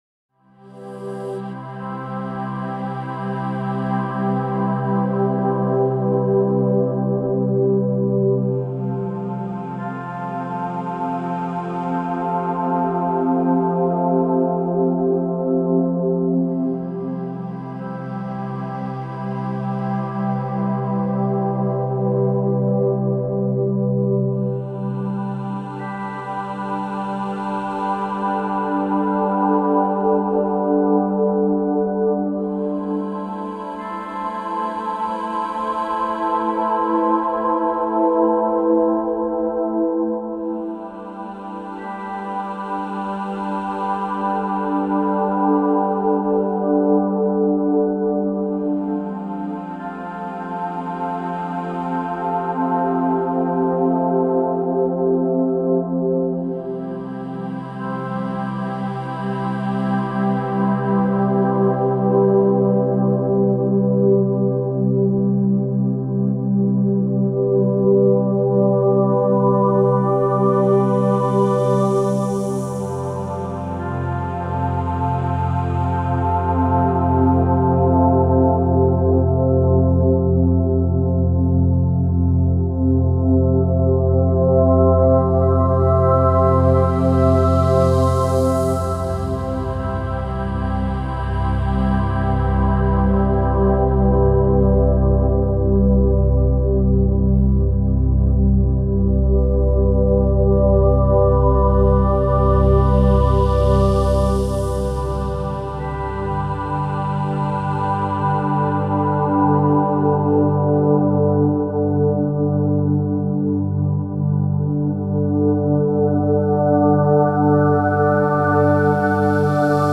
Description: Warm ambient relaxing synth pad music.
Genres: Relaxing Music
Tempo: slow